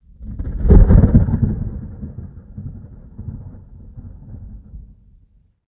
thunder3.ogg